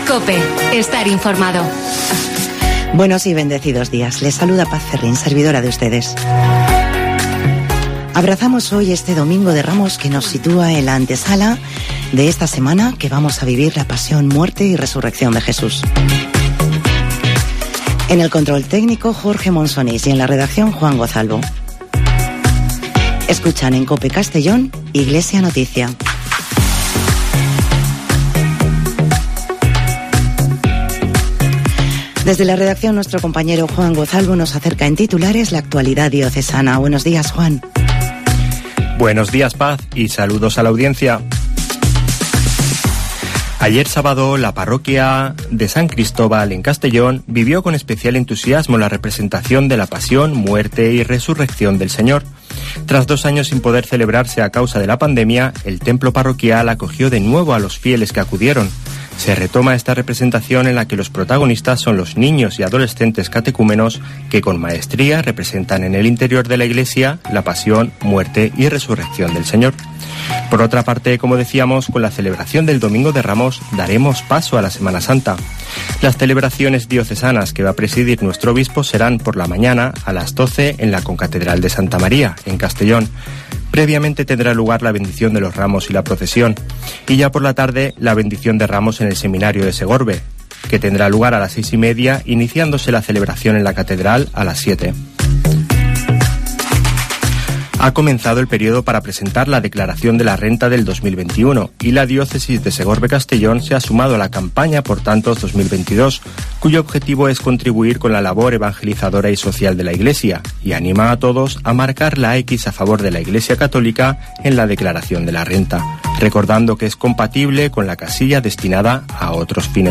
Espacio informativo